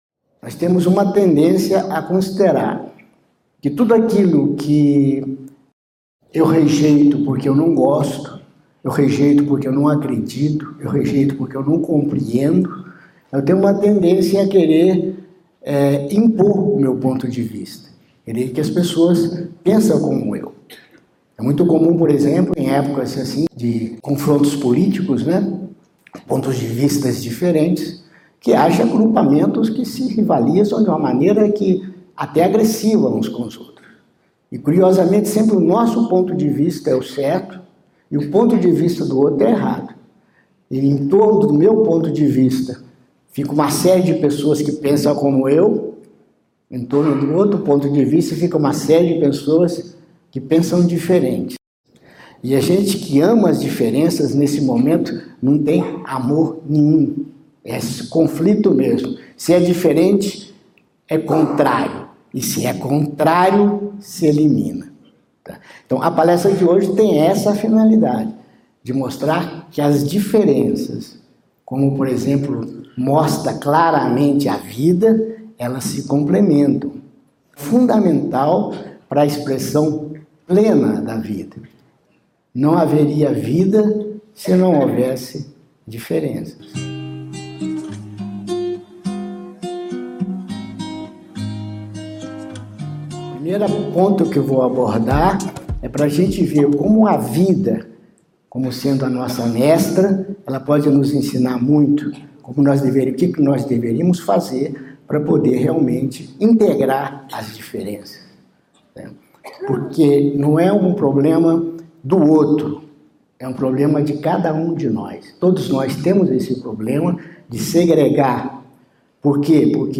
Nesta palestra